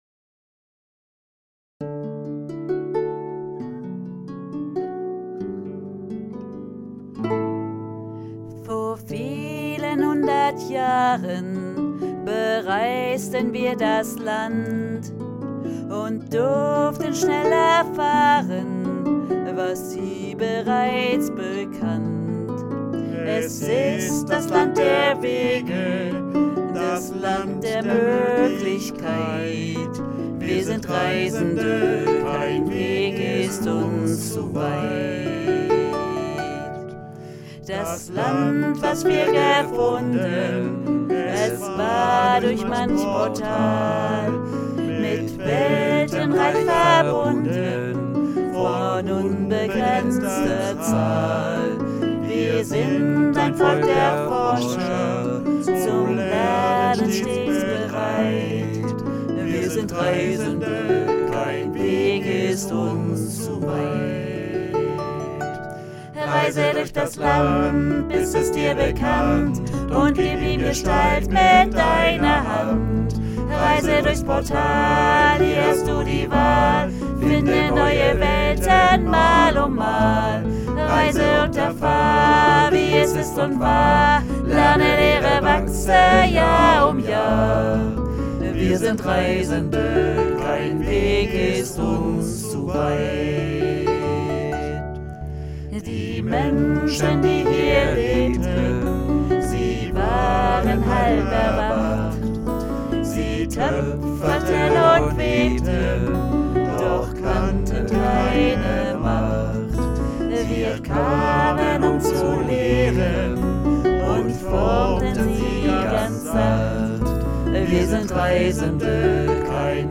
Tenor
Bariton